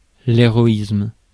Ääntäminen
Ääntäminen France: IPA: /e.ʁɔ.ism/ Haettu sana löytyi näillä lähdekielillä: ranska Käännös Ääninäyte Substantiivit 1. heroism US 2. valour US 3. prowess Suku: m .